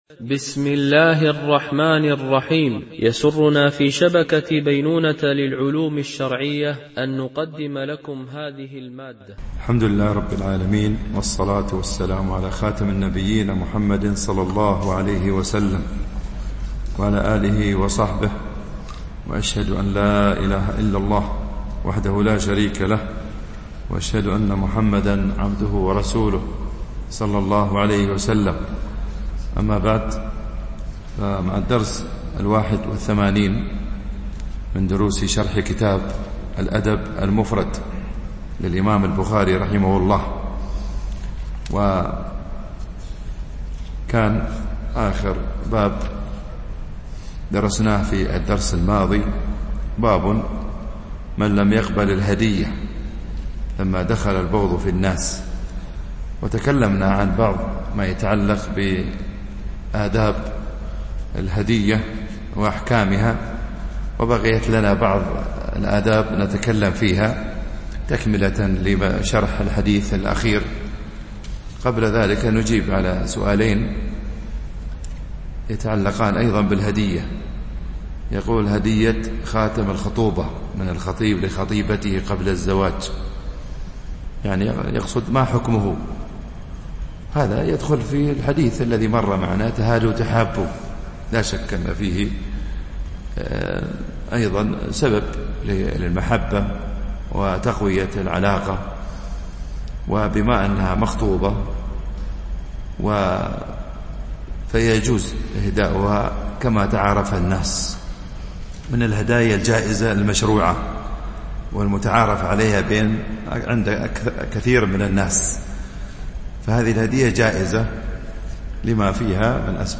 شرح الأدب المفرد للبخاري ـ الدرس81 ( الحديث 597 -601)
التنسيق: MP3 Mono 22kHz 32Kbps (CBR)